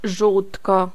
Ääntäminen
IPA : /jəʊk/